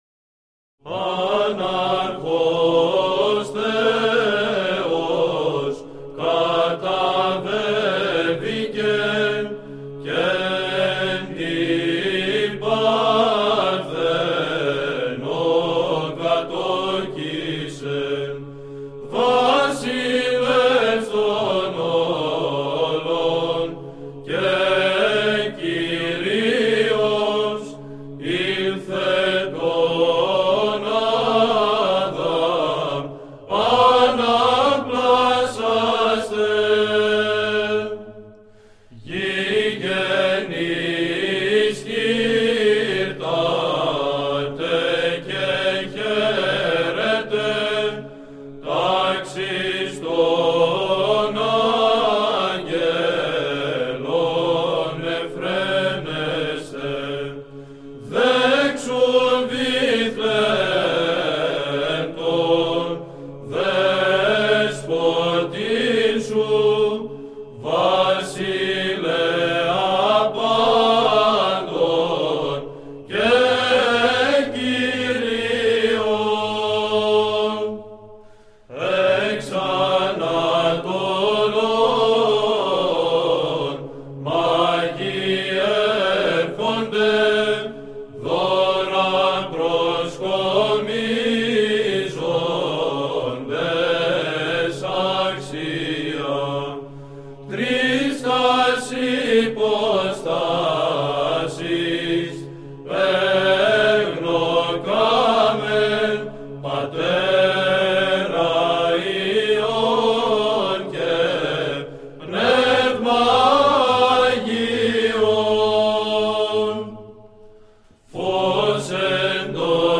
Αγιορείτικα κάλαντα, Ρωμέϊκα